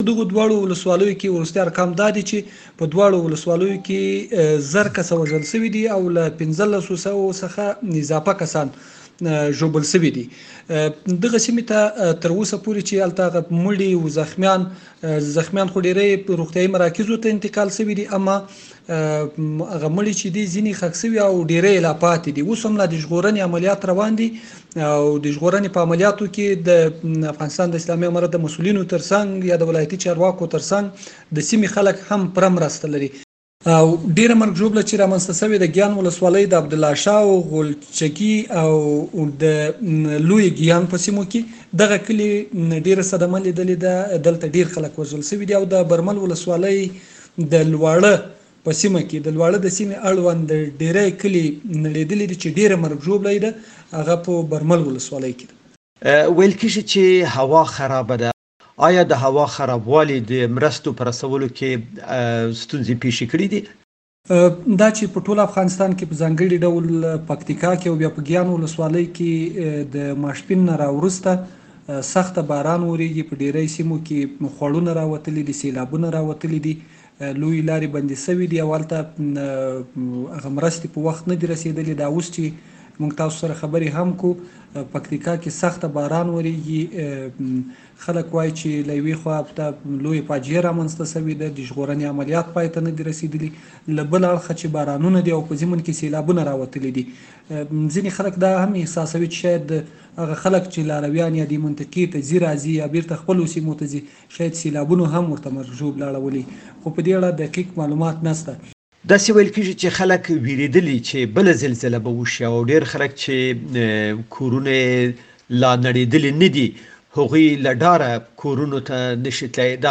مرکه